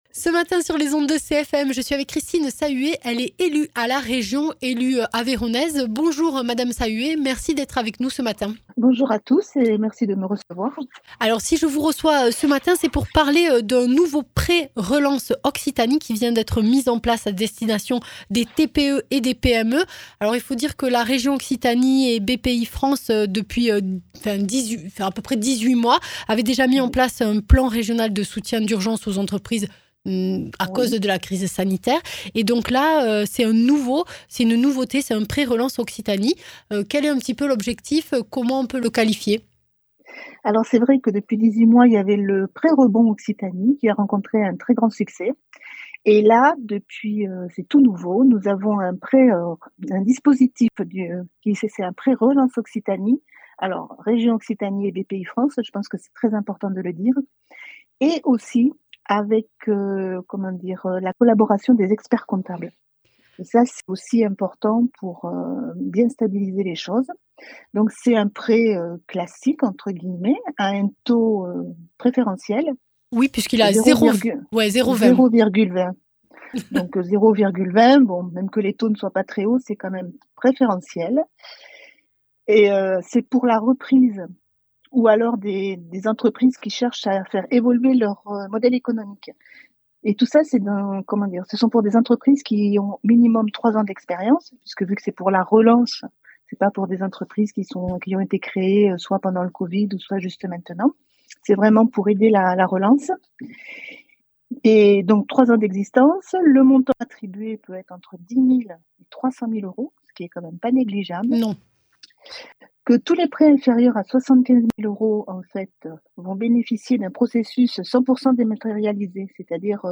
Interviews
Invité(s) : Christine Sahuet, conseillère régionale